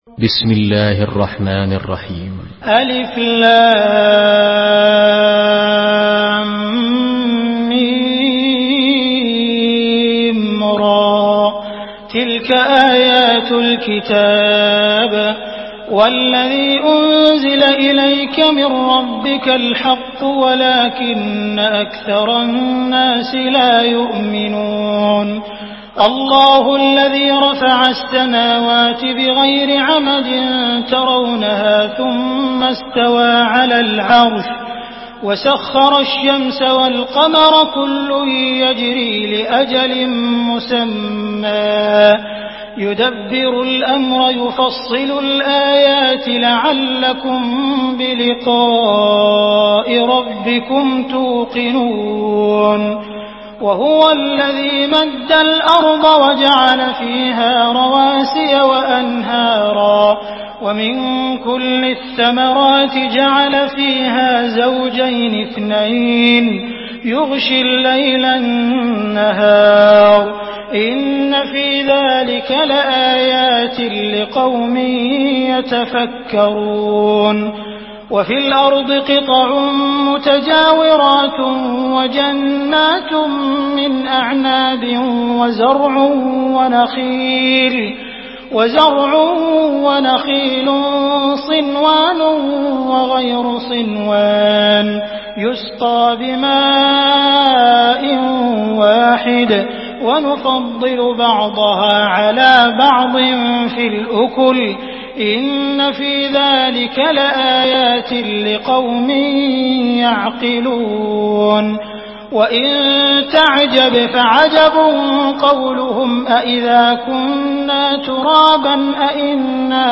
Surah Ar-Rad MP3 by Abdul Rahman Al Sudais in Hafs An Asim narration.
Murattal Hafs An Asim